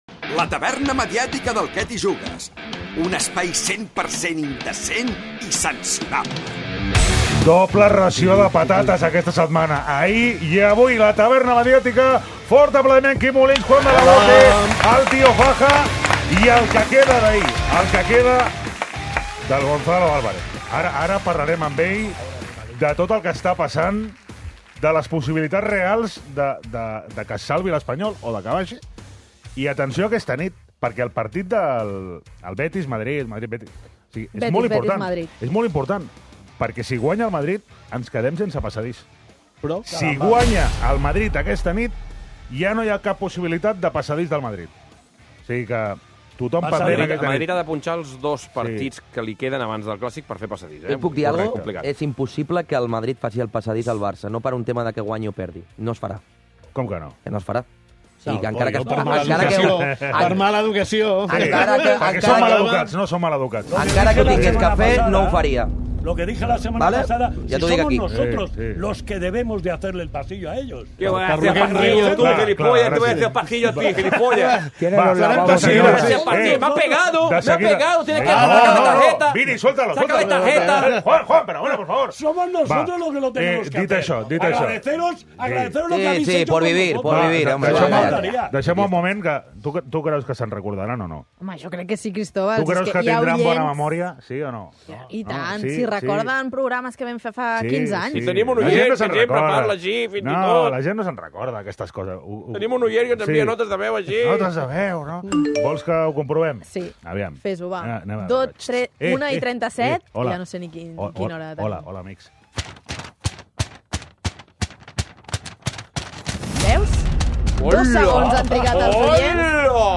Escolta la tertúlia més irreverent de la ràdio